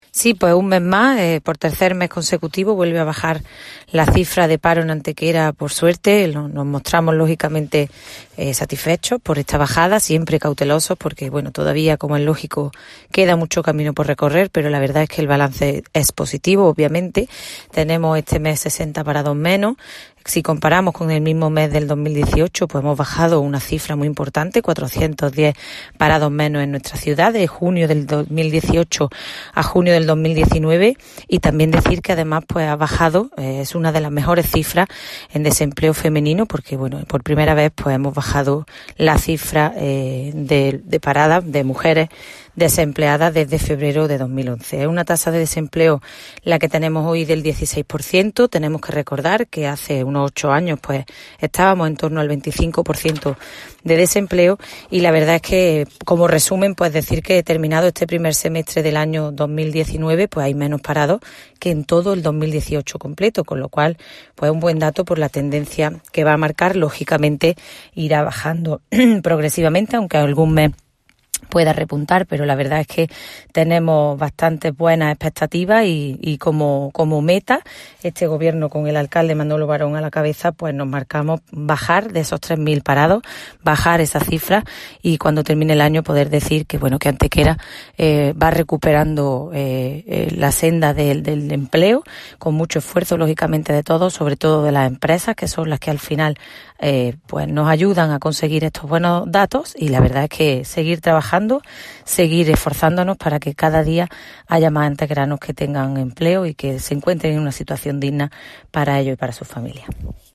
Generar Pdf martes 2 de julio de 2019 Valoración de los datos del paro del mes de junio Generar Pdf AUDIO Corte de audio de la teniente de alcalde Ana Cebrián valorando los datos de paro registrados en el mes de junio. Cortes de voz A. Cebrián 754.36 kb Formato: mp3